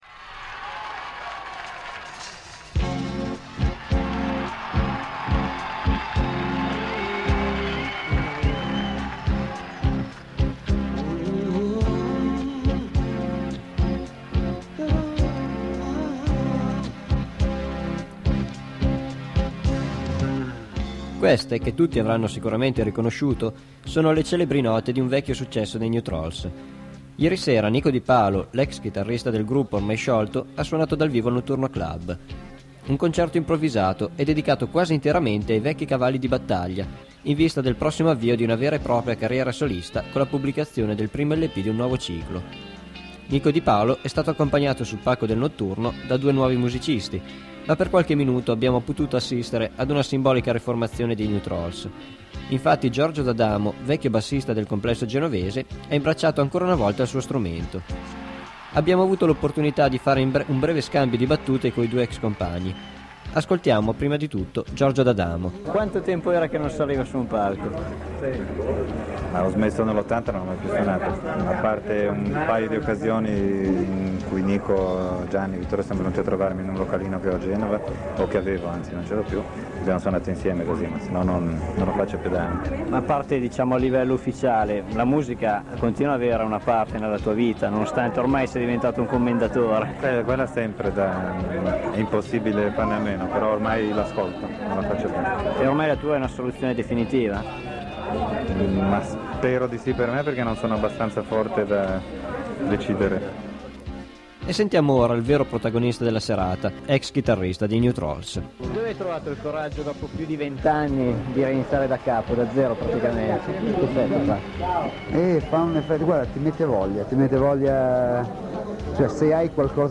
l'intervista
Due parole dopo il concerto. Ai microfoni di Radio West prima un rapido scambio di battute con il redivivo Giorgio D’Adamo: il bassista storico dei New Trolls, da molti anni lontano dalla scena musicale, per qualche minuto affiancò Di Palo sul palco del Notturno. A seguire l’intervista con il chitarrista.